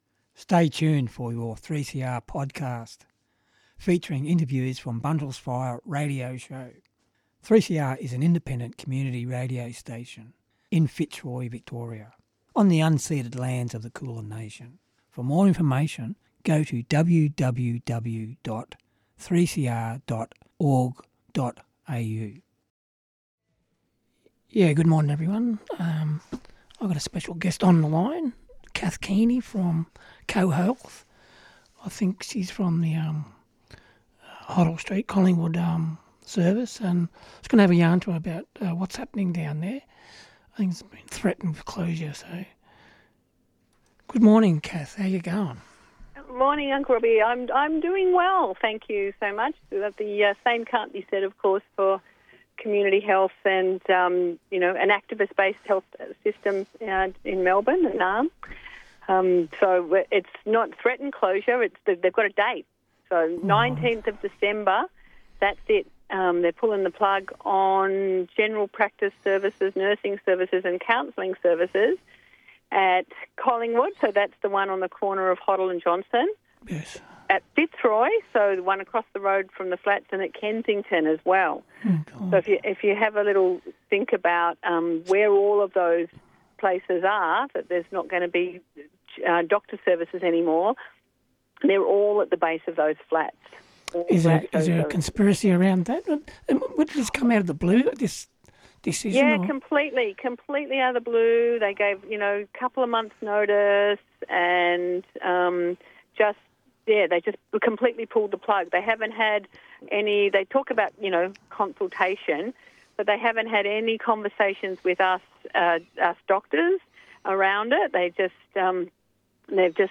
Fire First ignited on the 3CR airwaves in April 2004 as a unique program for 3CR, being an Indigenous and non-Indigenous collaboration looking at colonialism and activism towards justice. Since then it has served to fill a void in the mainstream media of an Aboriginal activist point of view, as well as serving to broadcast a developing conversation between co-presenters, guests and talk-back callers from both a black and white perspective.